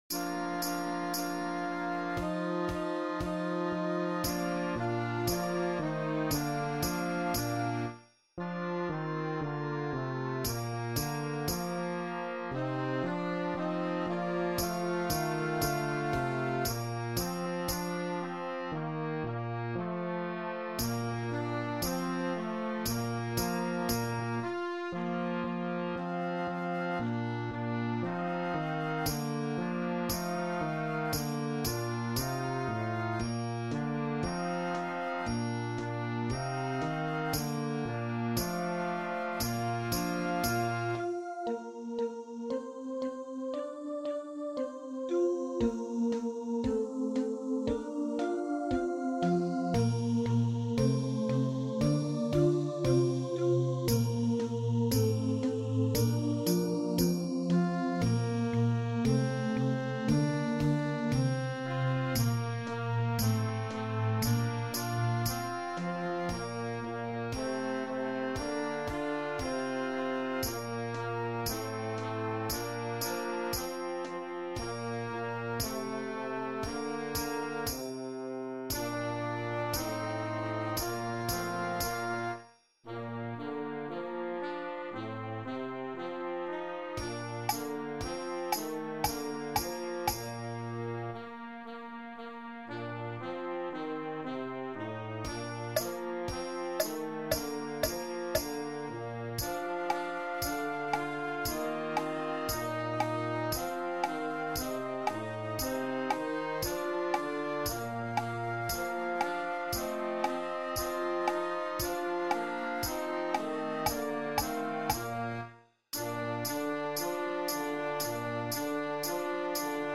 Written for beginner bands.